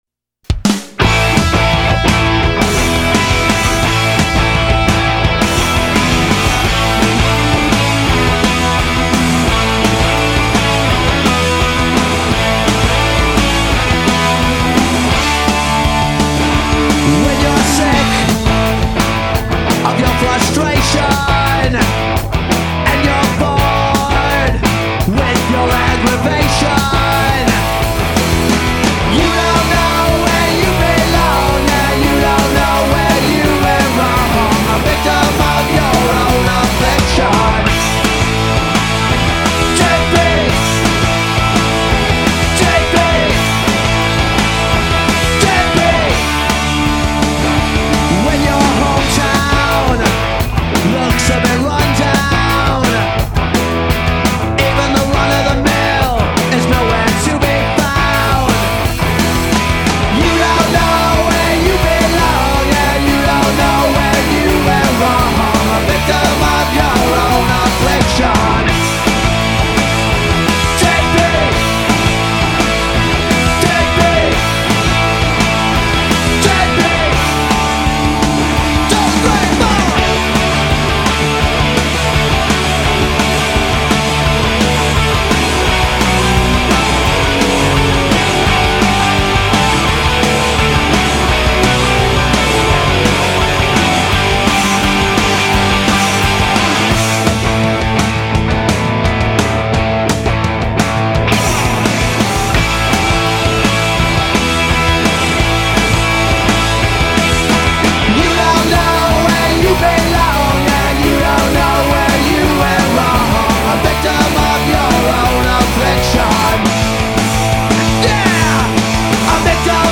Lead Guitar
Drums